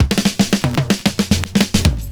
112FILLS06.wav